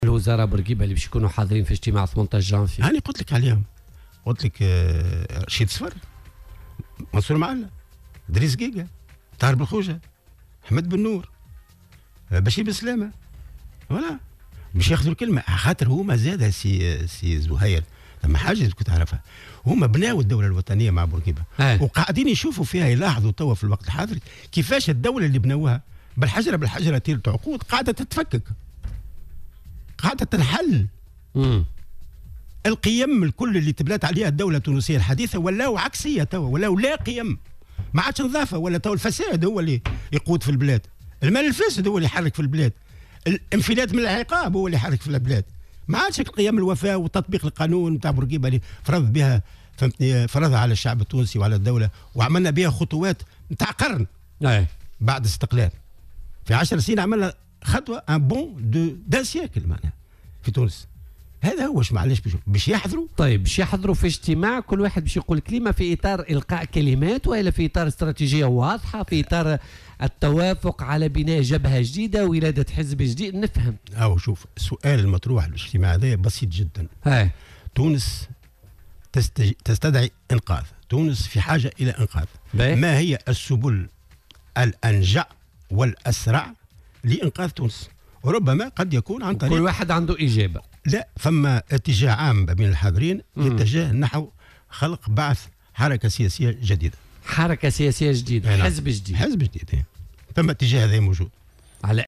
وأضاف في مداخلة له اليوم في برنامج "بوليتيكا" أن من بين الحضور رشيد صفر ومنصور معلى و الطاهر بلخوجة و أحمد بن نور و البشير بن سلامة وادريس قيقة وغيرهم..وأوضح أن الهدف من هذه المبادرة هو توحيد القوى الحداثية من أجل إيجاد حلول لإخراج البلاد من مأزقها، مشيرا إلى أنه من المنتظر أيضا الإعلان عن ميلاد حركة سياسية جديدة.